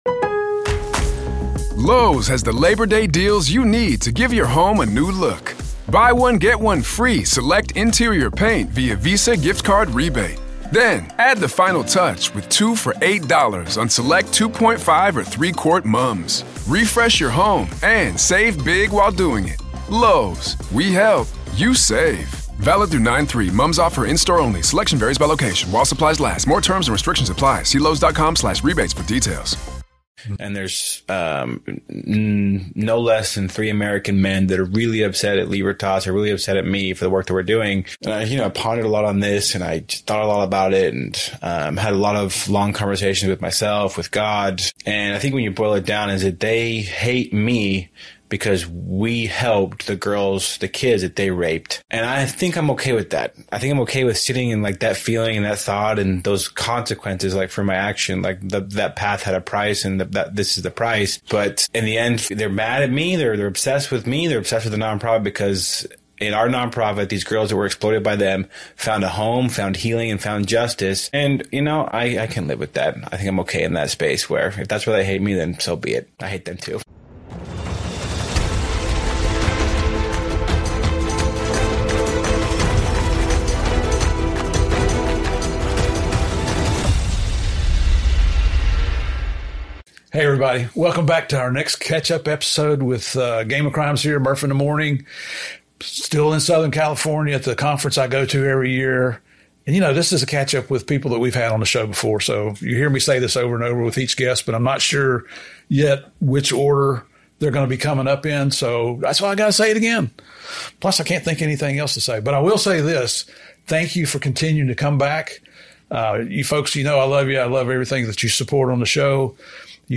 This episode is more than an interview—it’s a call to action.